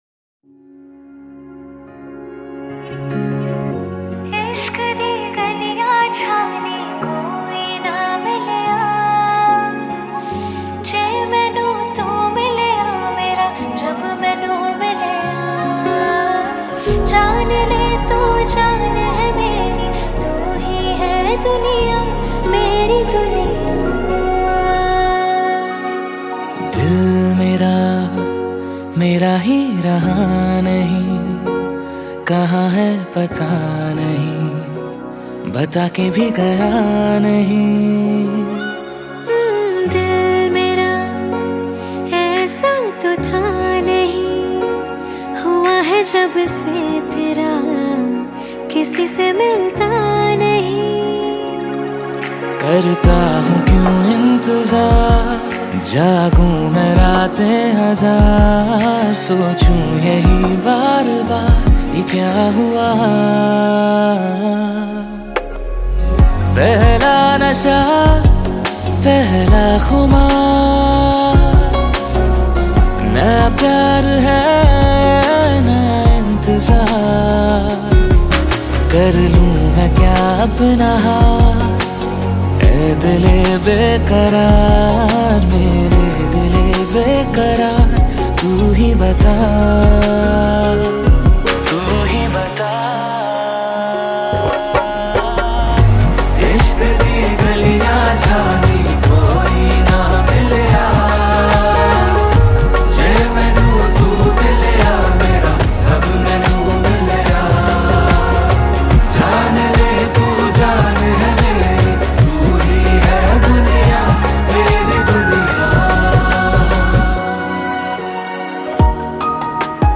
IndiPop